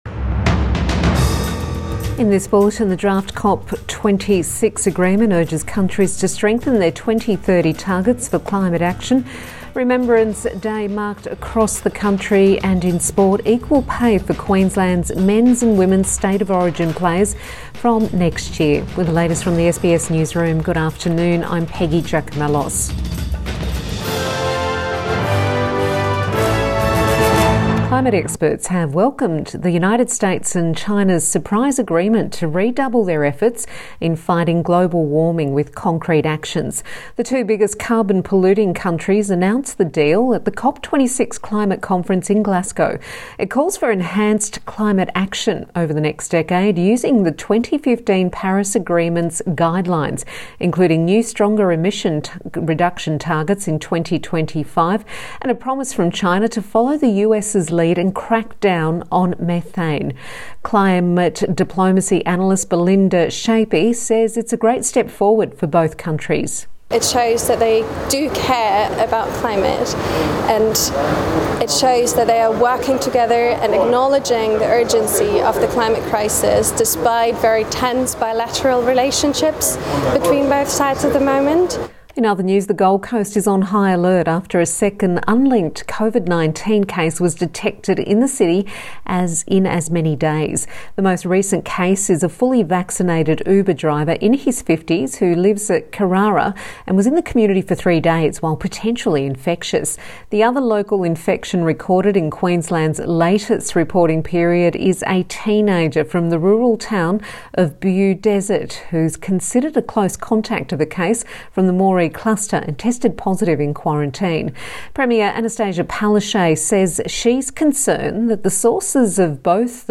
PM bulletin 11 November 2021